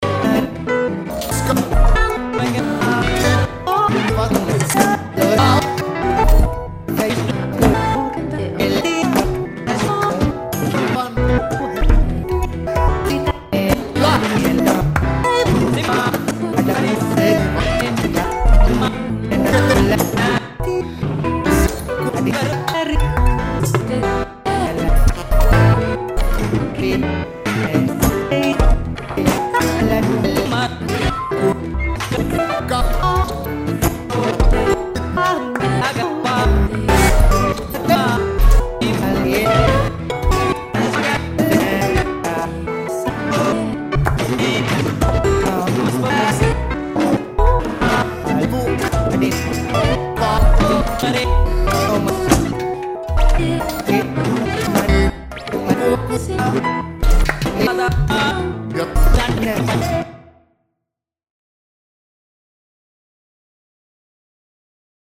It takes a directory of audio files, chops it, shuffles it, and frankensteins it up into a single audio file according to your BPM, effects and other settings.
Random full length finnish pop songs.
sloppyButcher-randomfinnishsongs.mp3